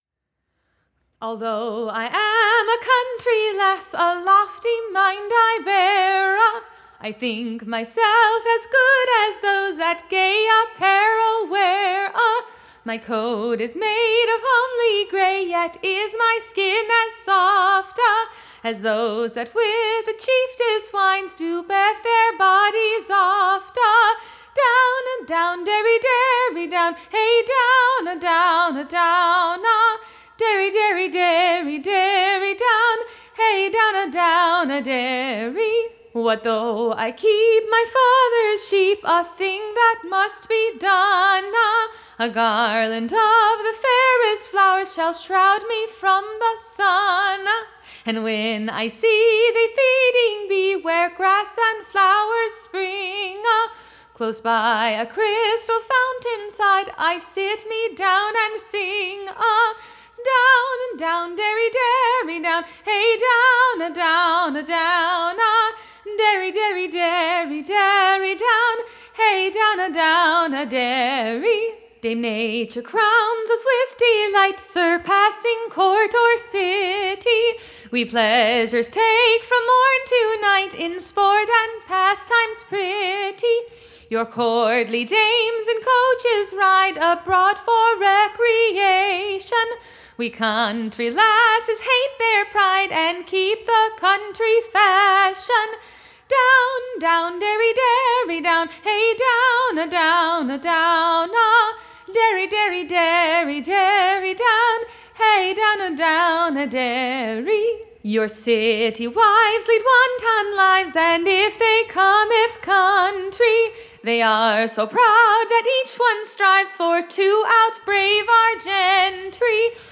Audio Companion to "The Broadside Ballad in Early Modern England"